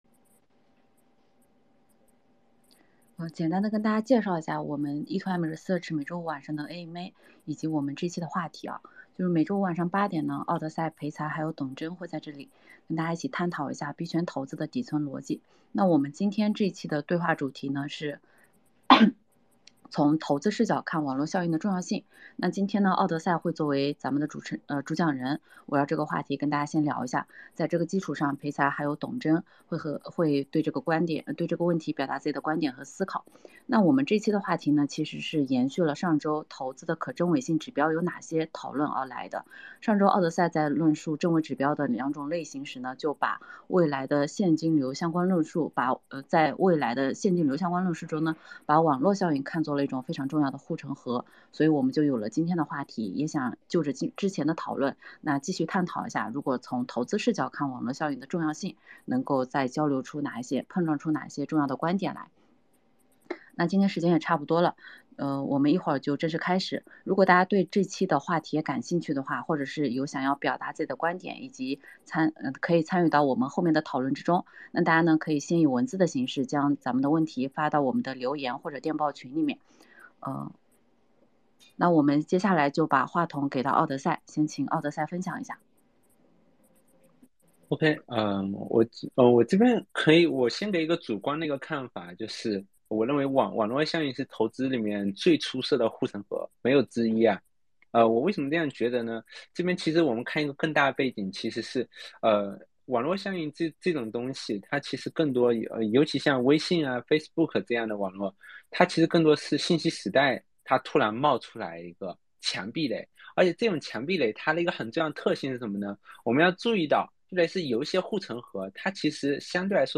前言概述 本周 E2M research 三位主讲人一起探讨《从投资视角看网络效应的重要性》 &nbsp